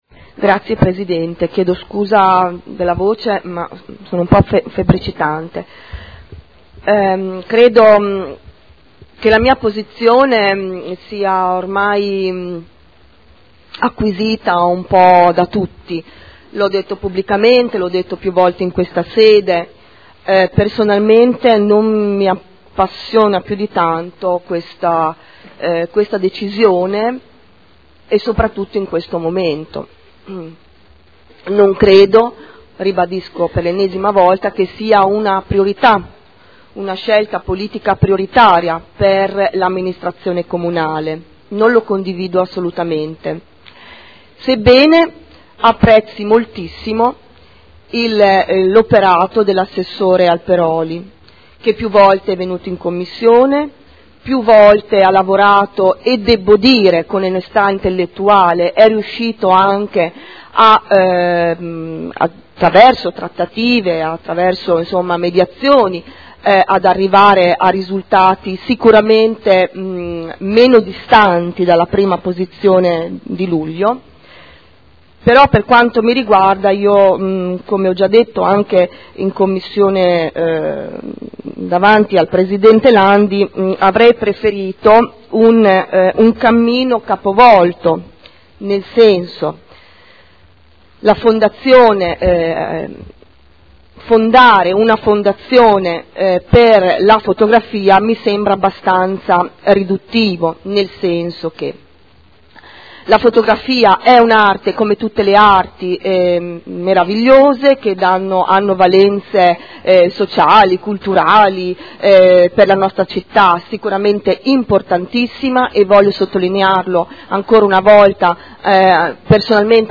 Seduta del 05/03/2012. Dibattito su emendamento e proposta di deliberazione. Fotomuseo Panini e Fondazione Fotografia - Indirizzi per la costituzione di una nuova Fondazione